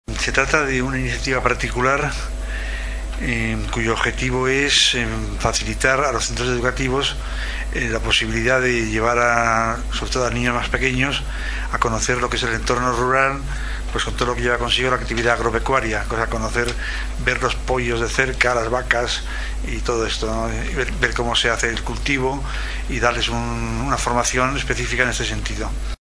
Al passat ple municipal es va aprovar el text refós del Pla especial per a la implantació d’una Aula d’entorn rural a la finca Mas Turon del veïnat de Sant Daniel.
El regidor d’urbanisme, Jose Carlos Villaro, explicava la proposta presentada.